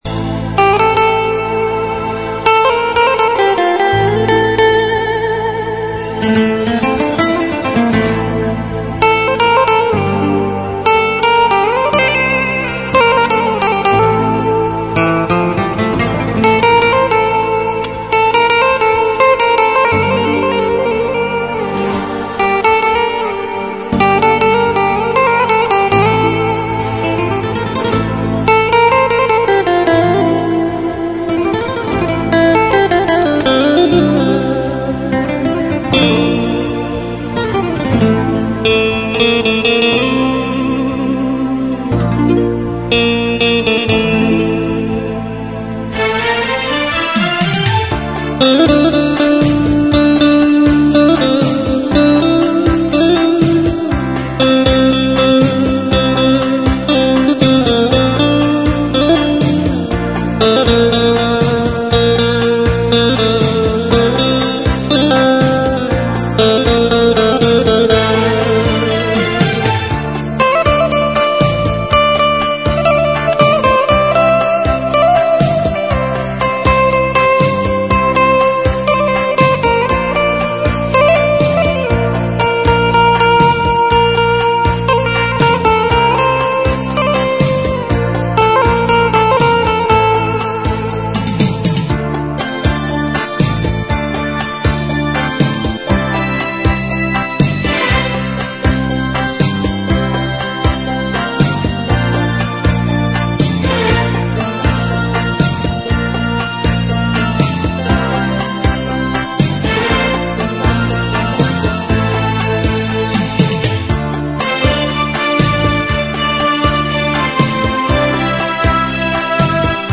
azerbaydzhanskaya_lyubov__gitara.mp3